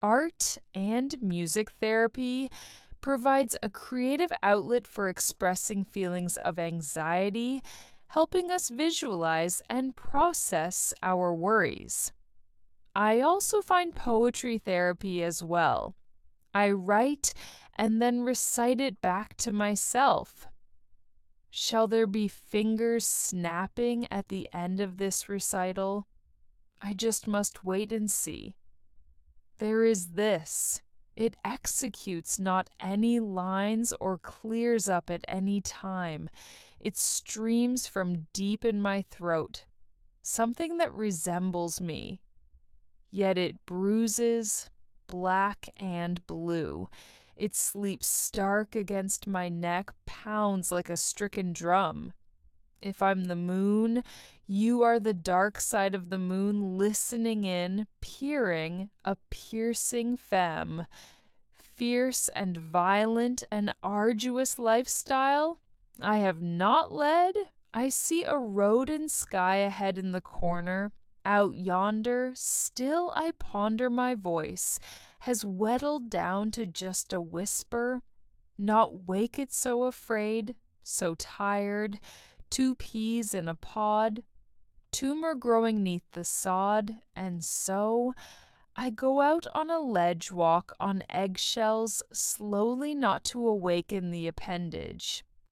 SO, it's close, yet not exact, i'm not there yet and so i use the voicemaster which i think makes a difference, easy for the listener, gives the eyes a rest.